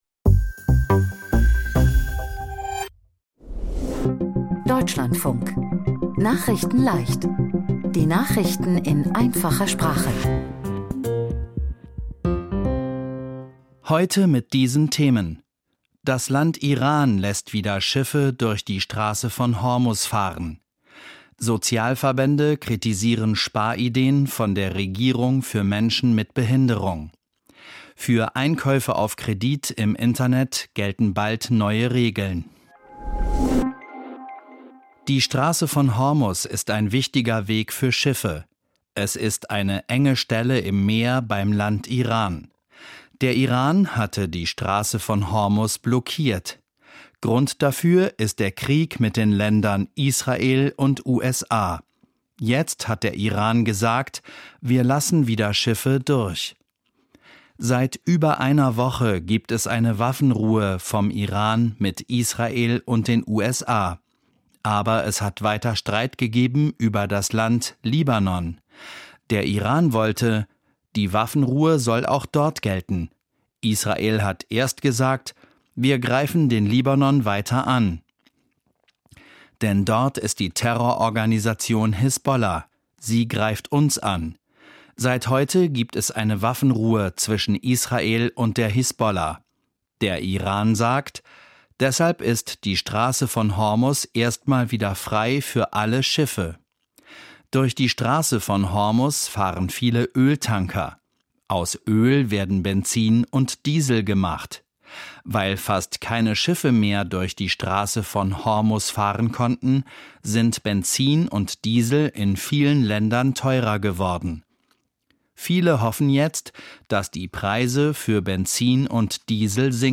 Nachrichten in Einfacher Sprache vom 17.04.2026